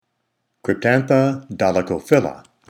Pronunciation/Pronunciación:
Cryp-tán-tha do-li-cho-phy'l-la